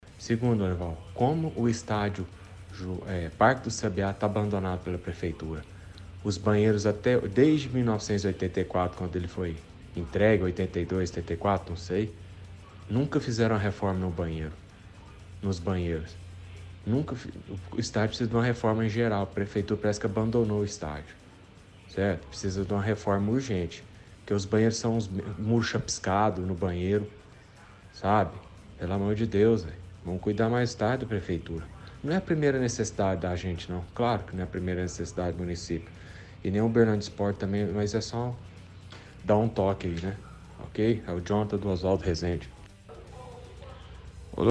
– Ouvinte fala sobre condições do estádio Sabiazinho, cobra a prefeitura de Uberlândia por reforma e mais atenção nos banheiros.